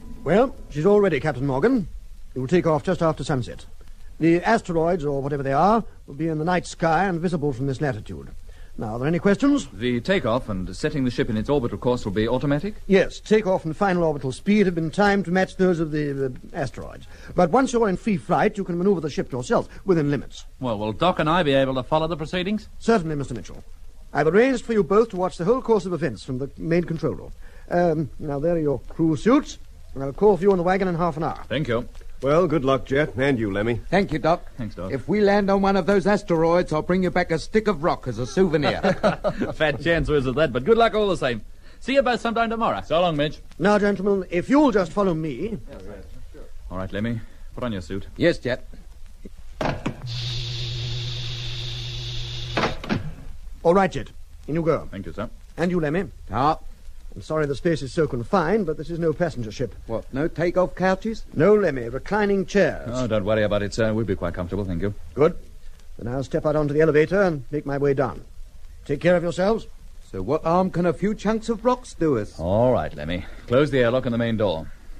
Journey vs Sprong: Opvallend - serie 3: deel 02 - Geronimo hoorspelen